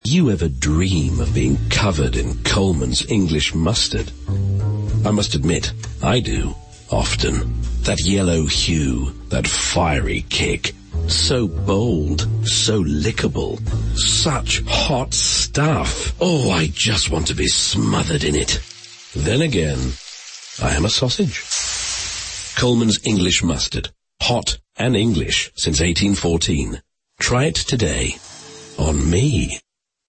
Colman’s uses theatre of the mind brilliantly because the listener builds their own picture of the scene with the dramatic (and somewhat suggestive) intimacy of the narrator’s voice, keeping us all guessing until the end of the ad when his identity is finally revealed. Yes, he is a sausage!